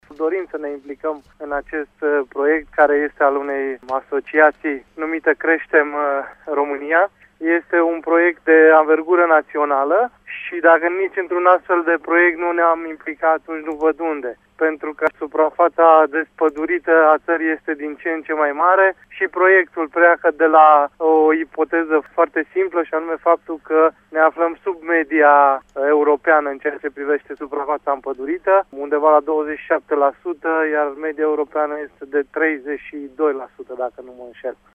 Prefectului județului Covasna, Sebastian Cucu, a declarat că instituția pe care o conduce are în vedere oportunitatea de a se ralia la acest proiect: